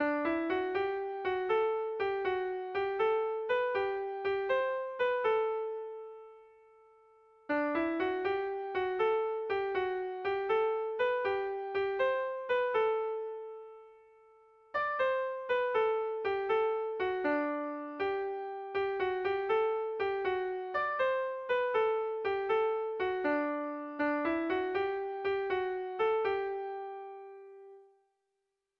Irrizkoa
Lau puntuko berdina, 8 silabaz
ABDE